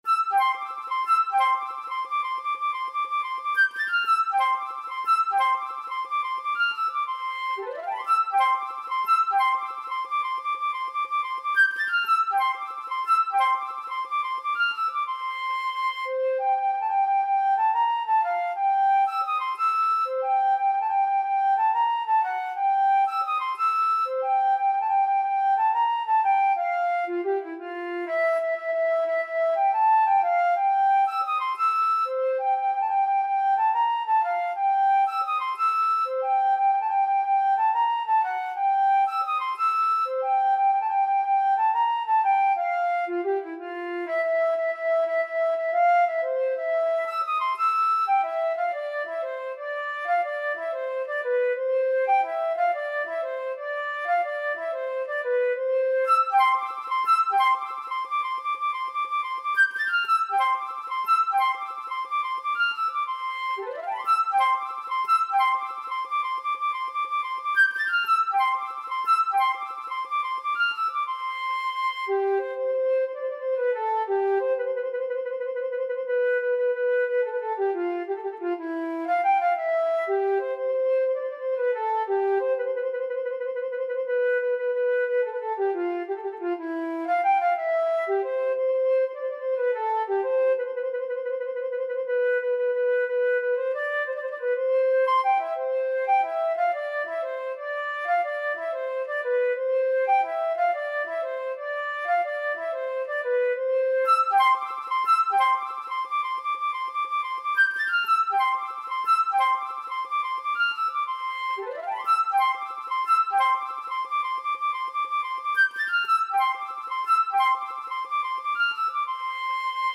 سطح : متوسط